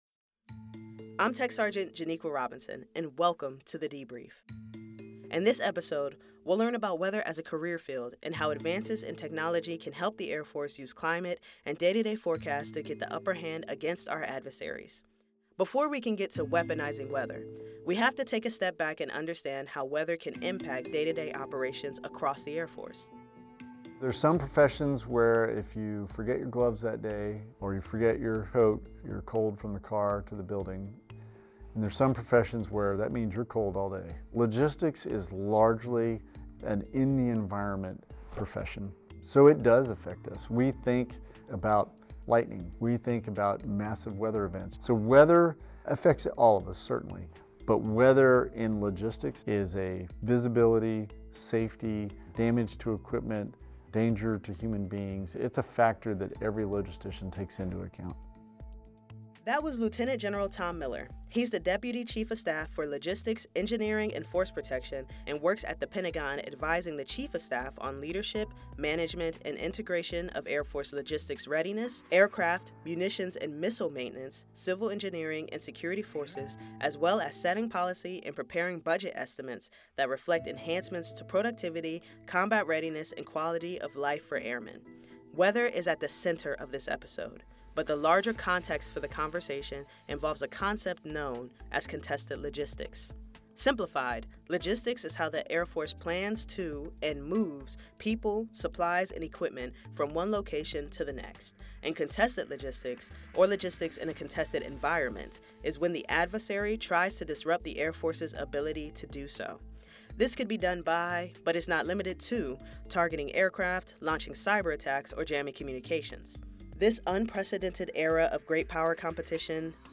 In this episode of ‘The Debrief,’ Lt. Gen. Tom Miller, deputy chief of staff for Logistics, Engineering and Force Protection and Col. Patrick Williams, the Air Force director of weather, explain the current security environment and weather Airmen’s role in Great Power Competition.